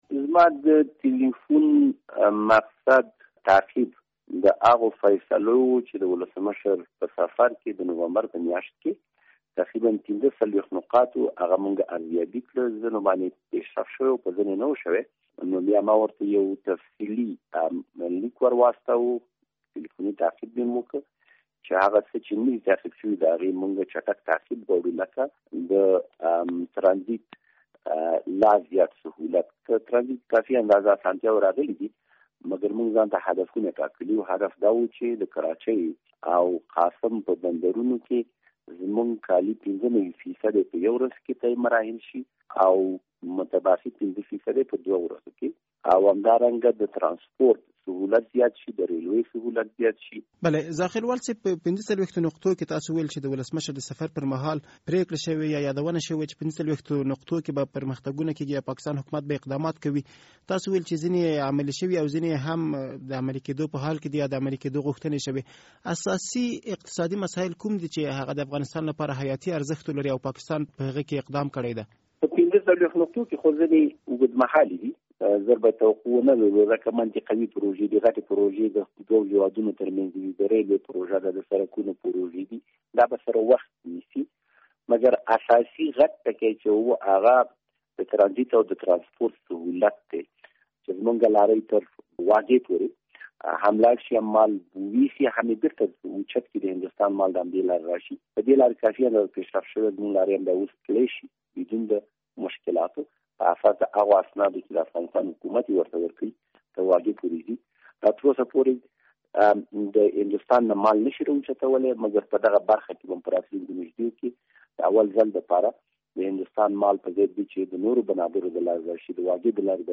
له عمر زاخیلوال سره مرکه